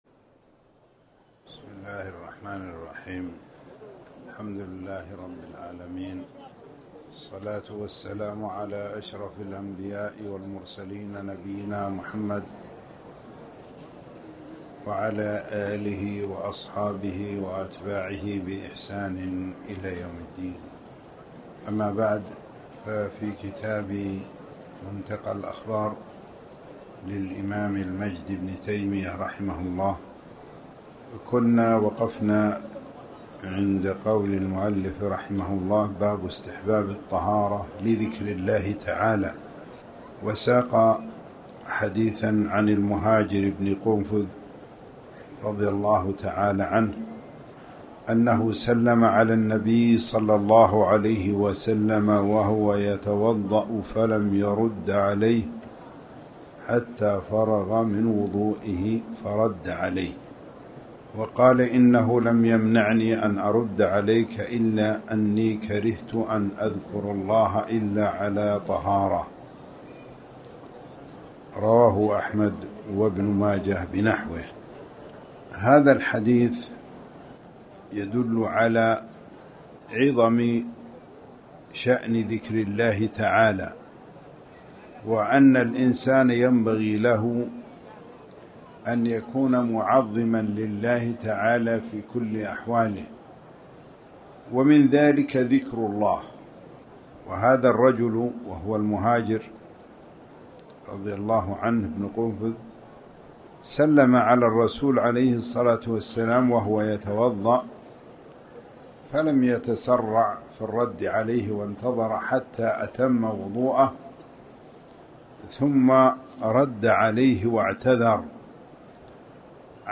تاريخ النشر ٢٢ ربيع الثاني ١٤٣٩ هـ المكان: المسجد الحرام الشيخ